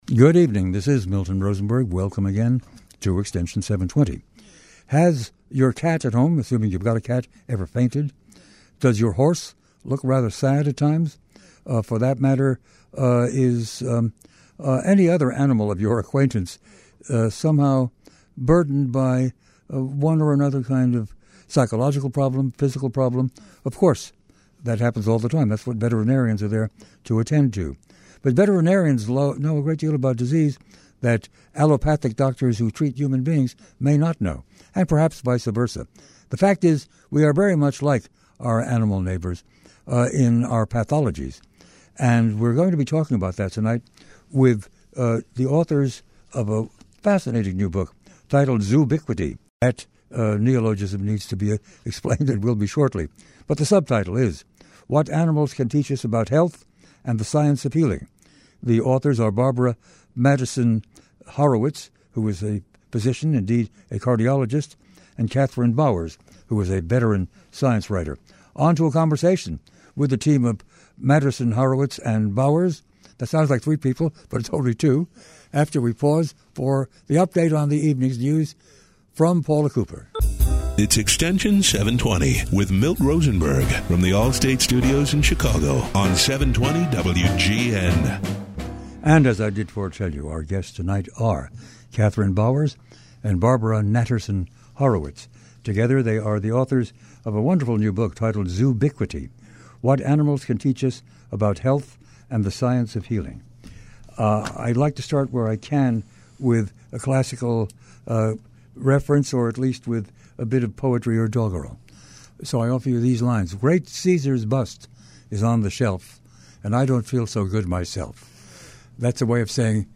This fascinating discussion explores the underpinnings and philosophical ramifications of how the study of animal health and well-being can and does guide advances in human health.